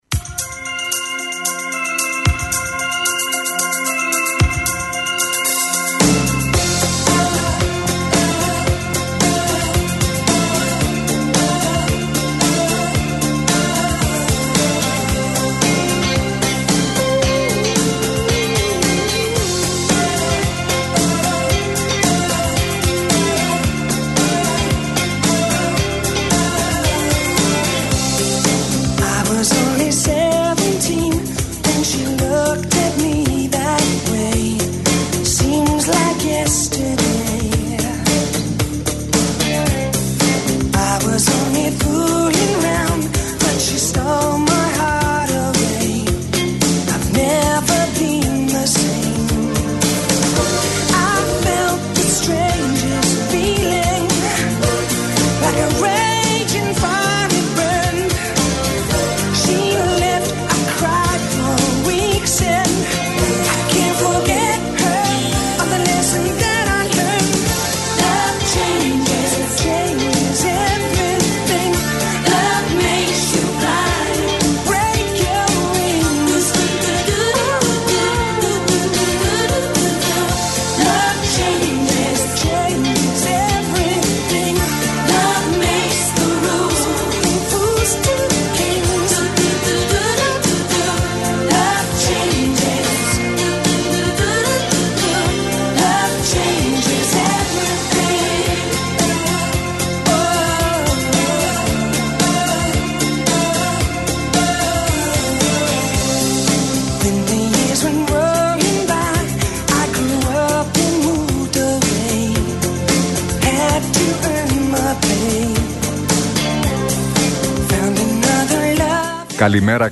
Ακούστε την εκπομπή του Νίκου Χατζηνικολάου στον ραδιοφωνικό σταθμό RealFm 97,8, την Δευτέρα 2 Ιουνίου 2025.